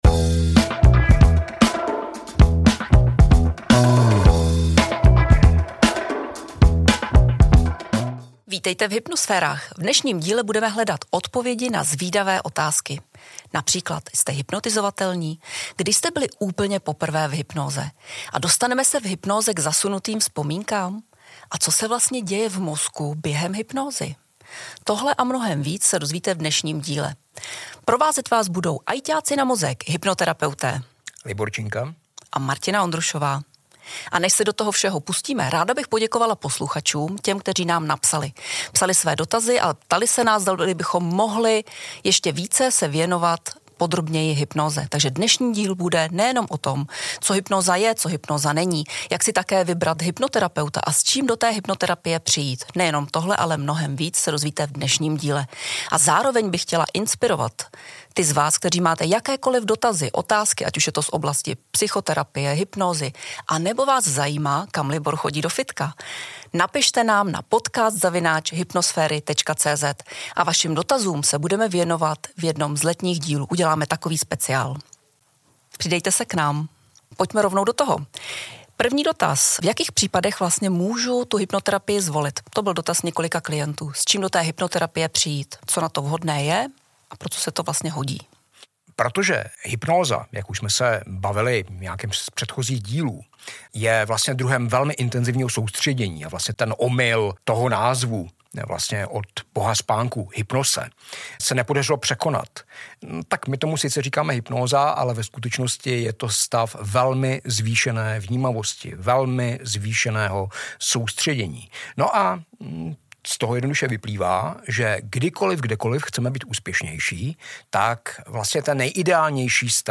Existuje mnoho podcastů na téma psychologie, psychoterapie, osobní rozvoj a toto je první podcast o zvládání života, cestách úspěchu a praktickém používání lidské mysli vedený dvěma hypnoterapeuty.